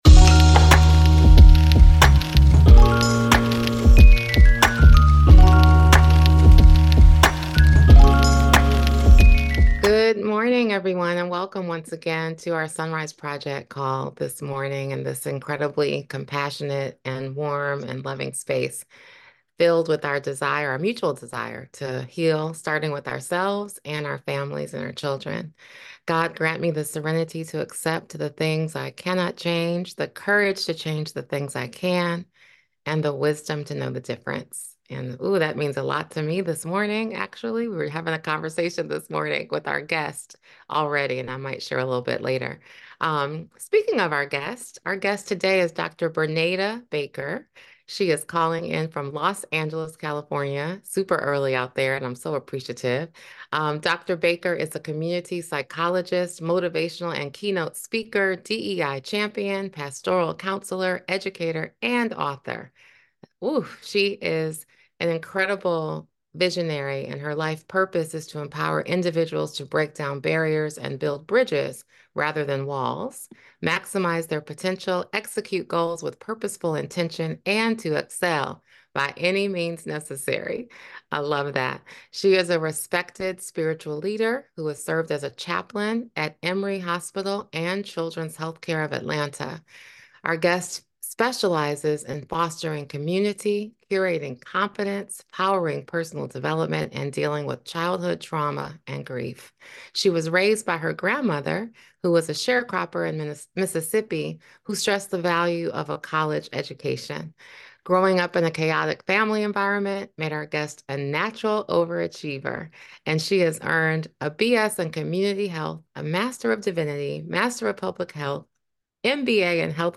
Tune in for a heart-centered conversation on how to honor the journey of parenthood without losing sight of personal well-being.